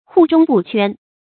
怙終不悛 注音： ㄏㄨˋ ㄓㄨㄙ ㄅㄨˋ ㄑㄨㄢ 讀音讀法： 意思解釋： 有所恃而終不悔改。